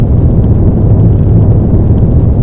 q3rally/baseq3r/sound/weapons/rocket/rockfly.ogg at 23822f6bef54cf193ace5c877ed35c5e900c8ef4